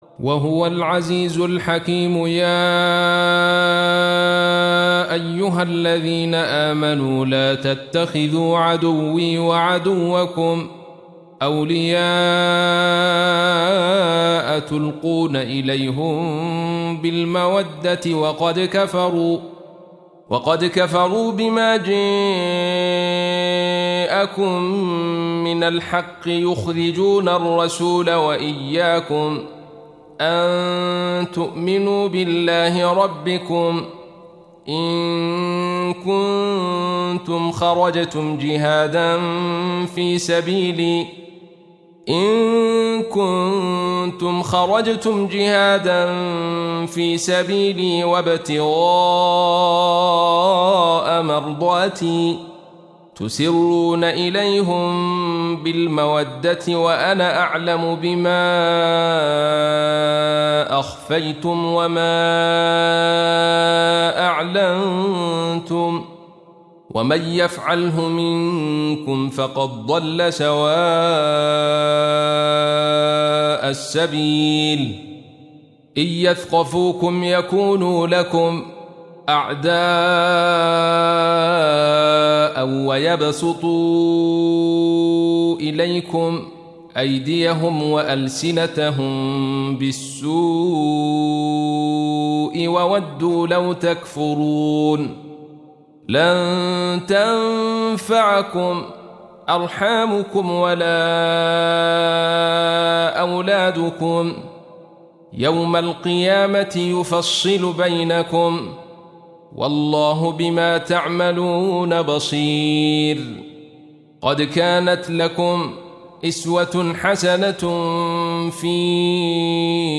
Surah Repeating تكرار السورة Download Surah حمّل السورة Reciting Murattalah Audio for 60. Surah Al-Mumtahinah سورة الممتحنة N.B *Surah Includes Al-Basmalah Reciters Sequents تتابع التلاوات Reciters Repeats تكرار التلاوات